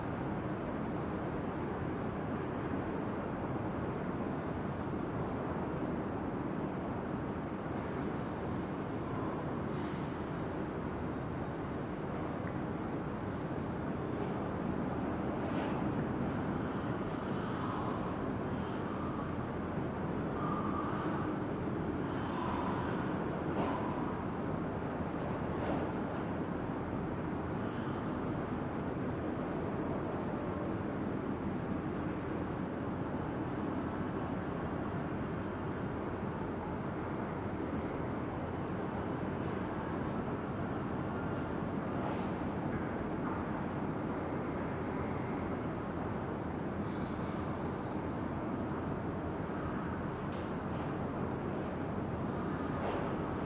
钳形纸
Tag: 大声的